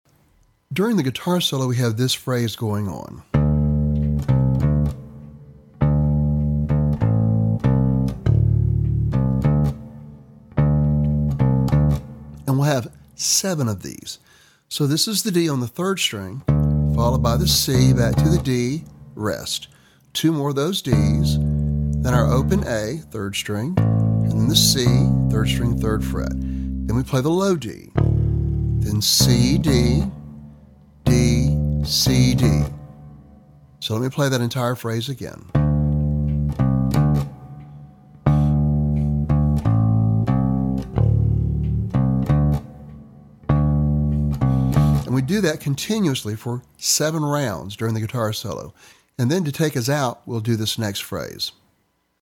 Lesson Sample
For Bass Guitar.